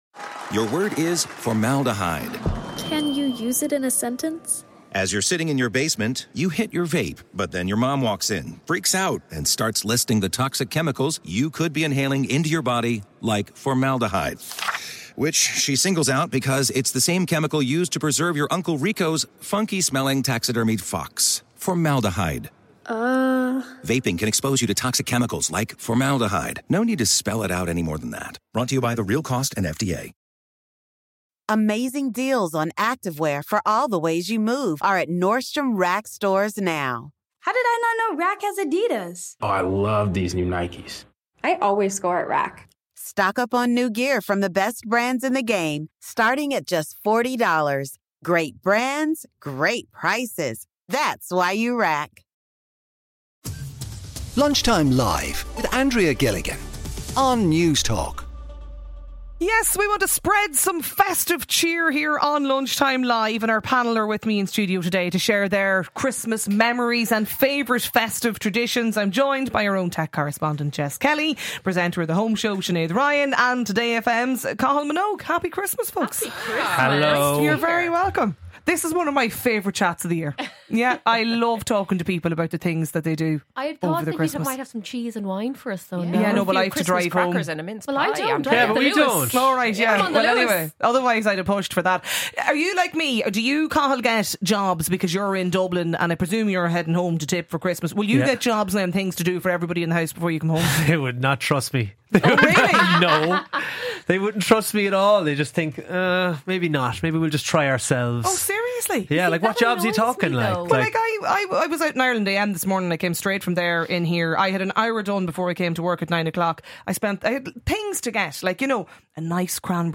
invites callers to have their say on the topics of the day